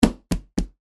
Звуки стука в дверь
Звук: ладошка стучит в дверь